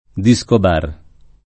[ di S kob # r ]